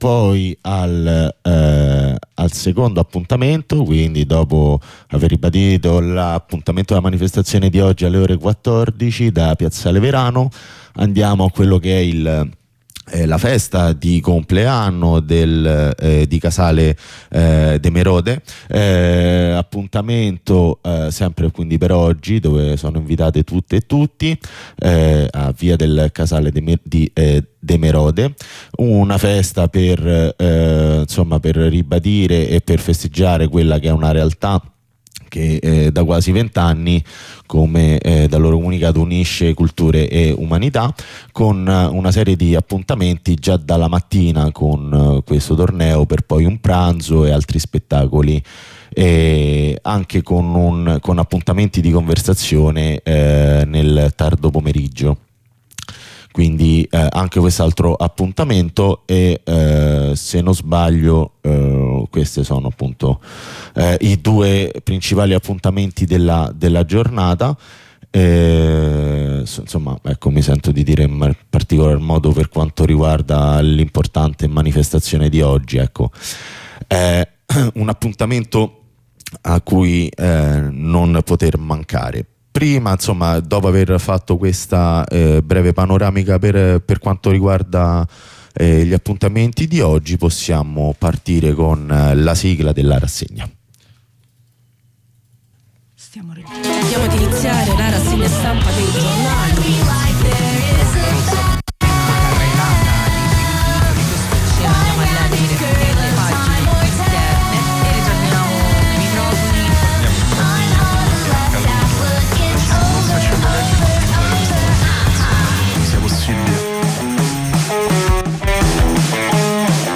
Lettura e commento dei quotidiani di sabato 14 dicembre 2024